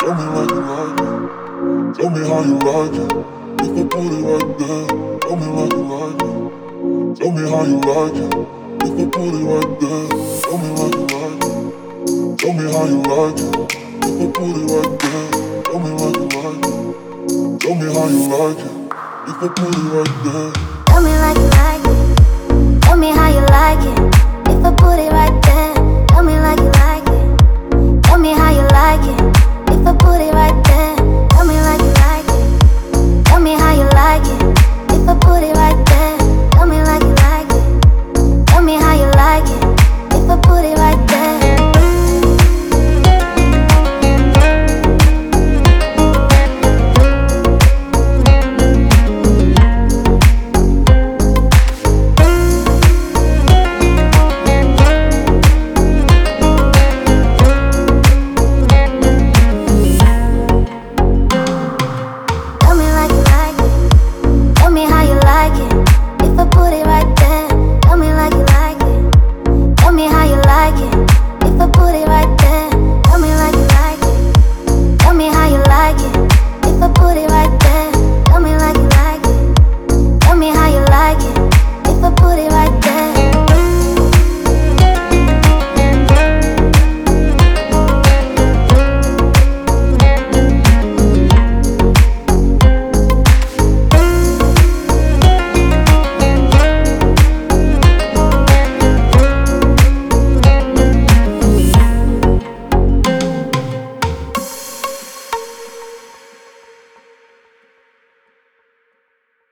Категория: Deep House музыка
Дип Хаус музыка